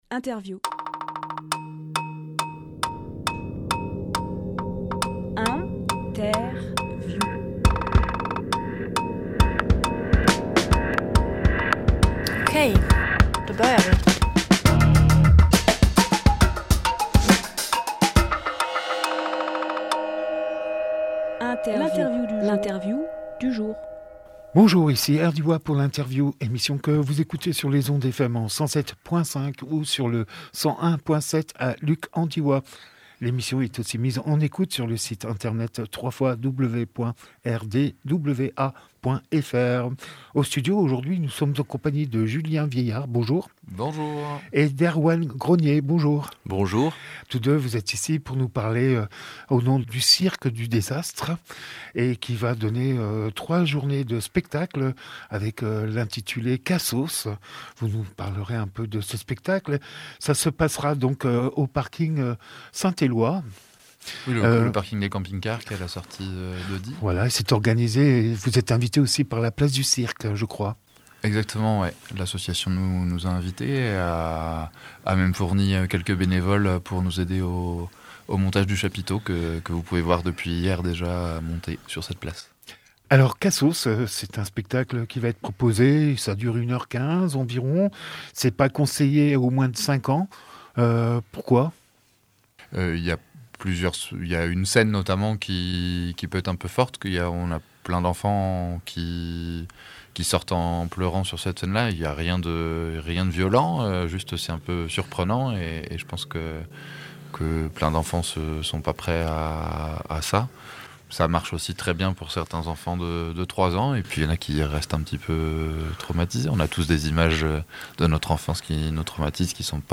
Emission - Interview Cassos par le Cirque du désastre Publié le 26 avril 2023 Partager sur…
Lieu : Studio RDWA